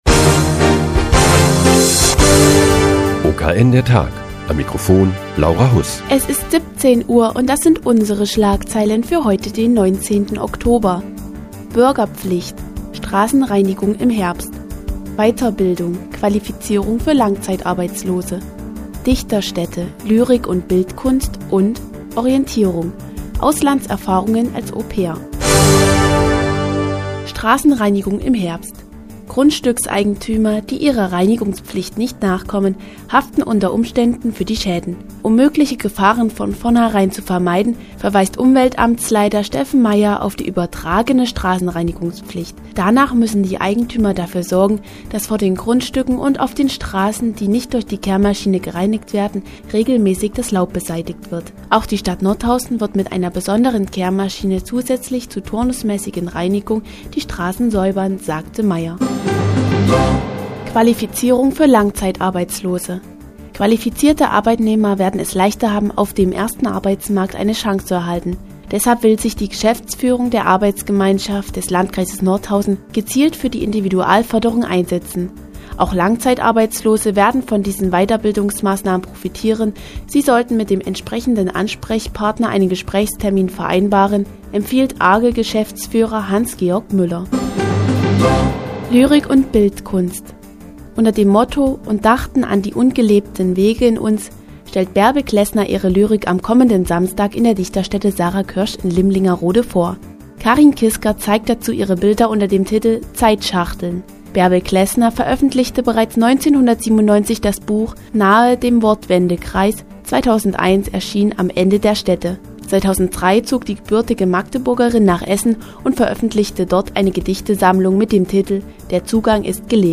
Die tägliche Nachrichtensendung des OKN ist nun auch in der nnz zu hören. Heute geht es um Qualifizierung für Langzeitarbeitslose und Lyrik in der Dichterstätte "Sarah Kirsch".